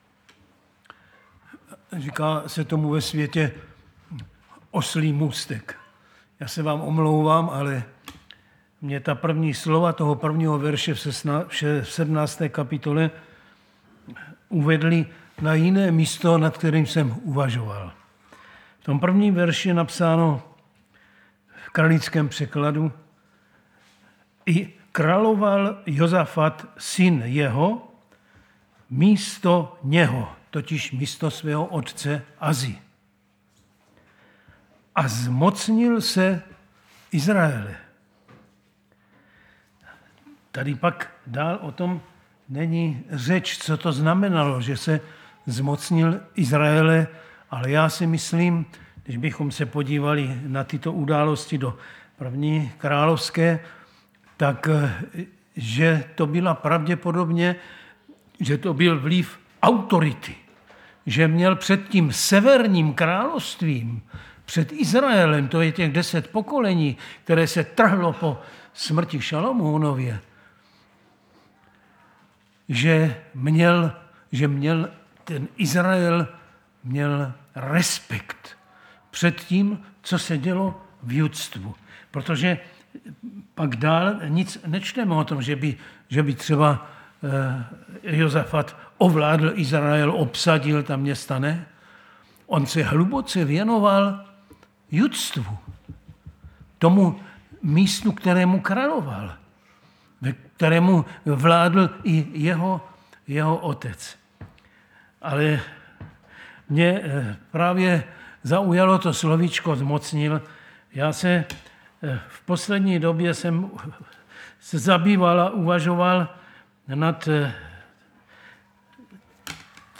Záznamy z bohoslužeb